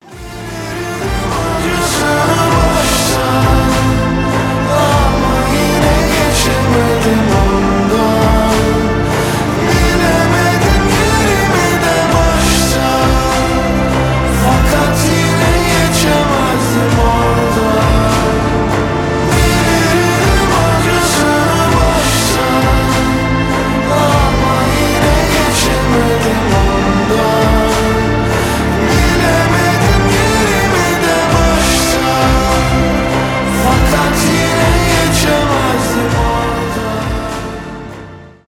альтернатива , pop rock